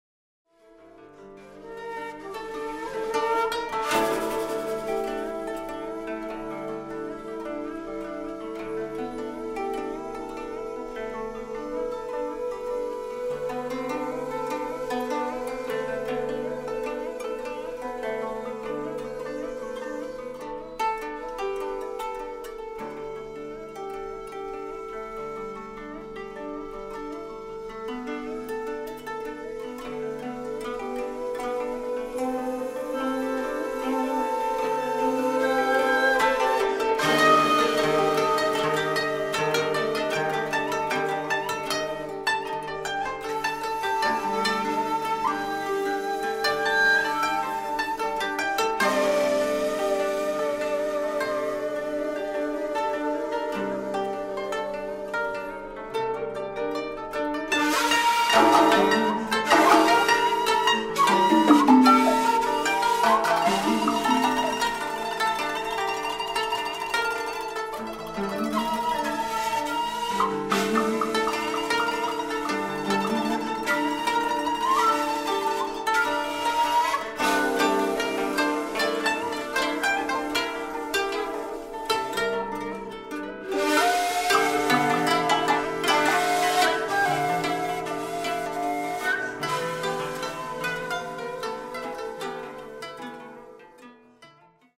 shakuhachi, biwa, & 21-string koto
with ensemble of Japanese instruments
solo shakuhachi, solo biwa, solo 21-string koto